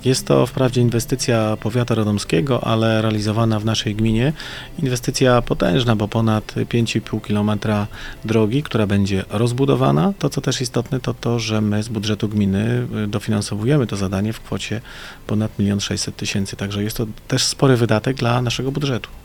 Najważniejszą i największą inwestycją jest ponad pięciokilometrowy odcinek drogowy na trasie Rajec – Kozłów – Wojciechów. Mówi wójt Jastrzębi, Wojciech Ćwierz: